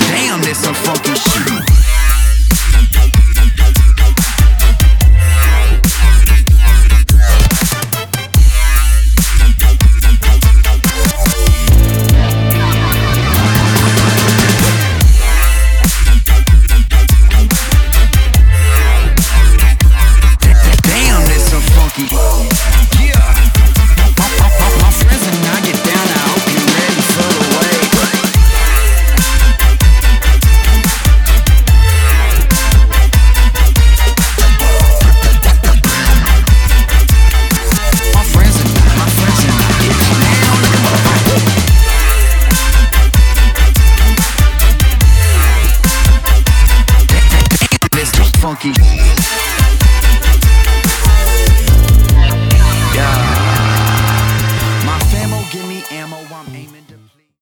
• Качество: 320, Stereo
мужской вокал
громкие
dance
Electronic
EDM
мощные басы
Blues
jazz